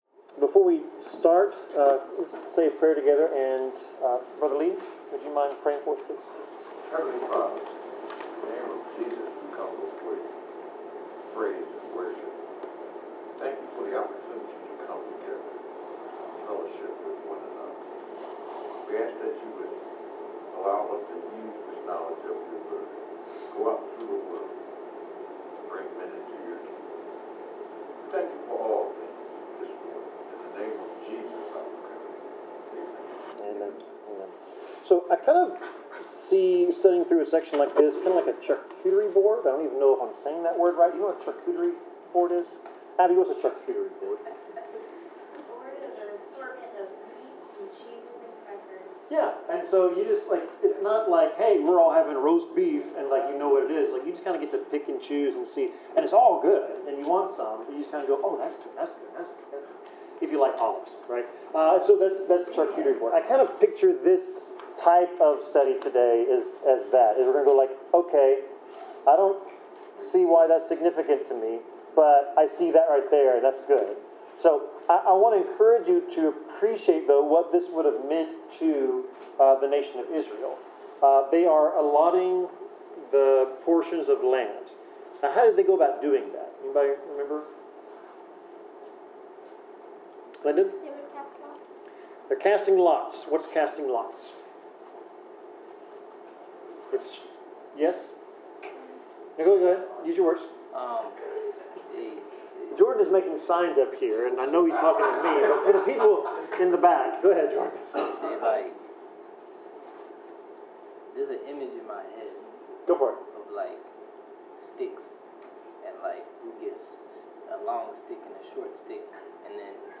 Bible class: Joshua 18-21
Service Type: Bible Class